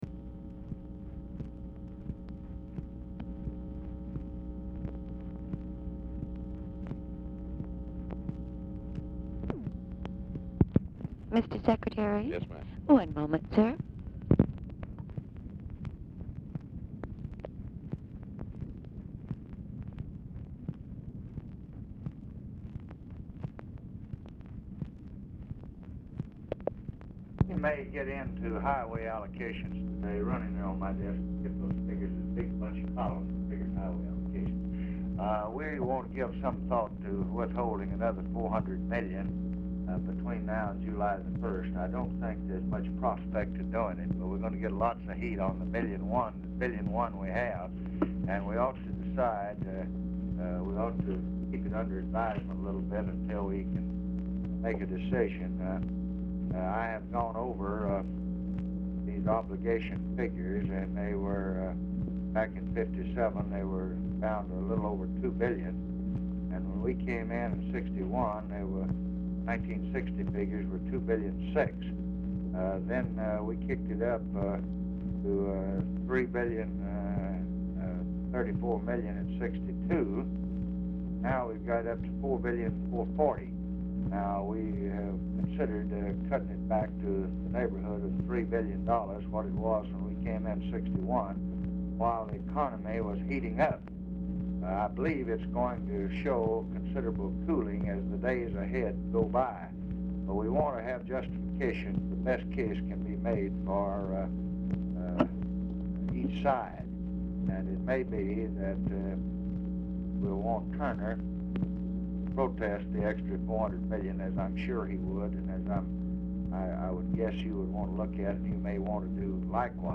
Telephone conversation # 11367, sound recording, LBJ and ALAN BOYD, 1/18/1967, 11:31AM | Discover LBJ
BOYD ON HOLD 0:28; RECORDING STARTS AFTER CONVERSATION HAS BEGUN; LBJ SPEAKS BRIEFLY TO SOMEONE IN HIS OFFICE AT BEGINNING OF CALL
Format Dictation belt